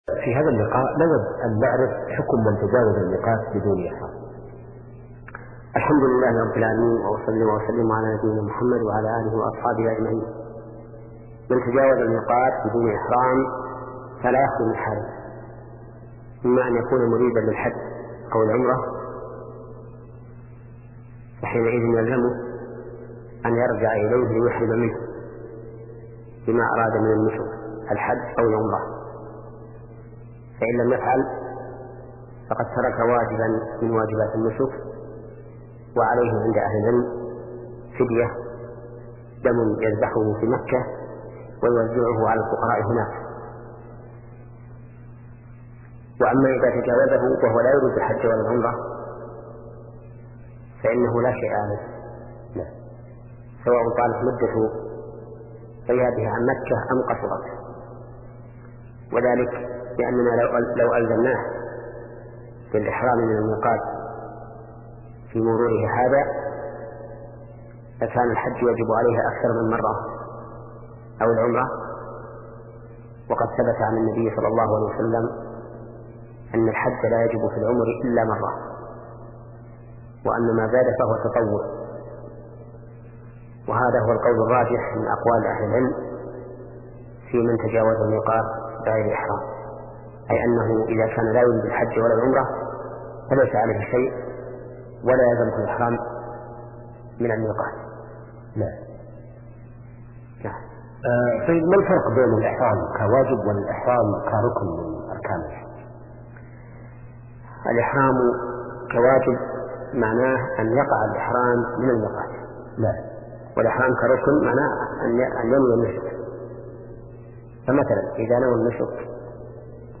شبكة المعرفة الإسلامية | الدروس | فقه العبادات (39) |محمد بن صالح العثيمين